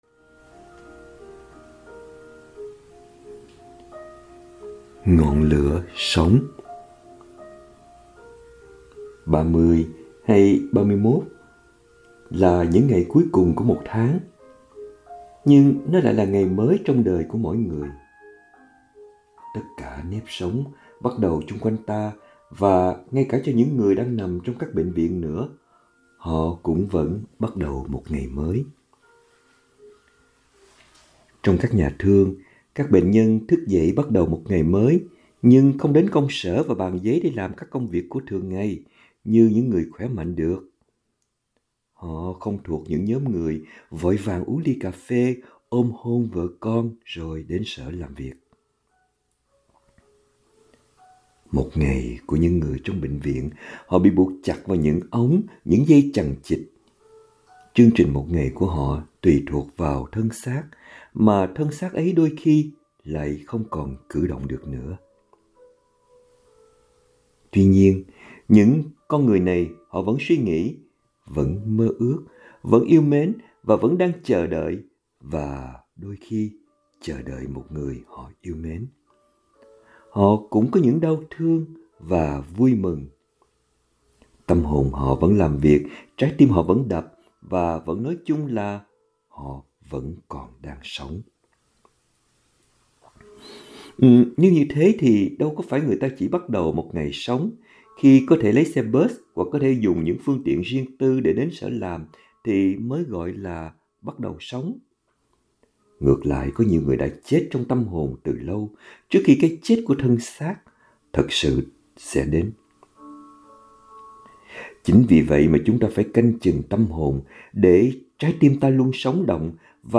2026 Audio Suy Niệm https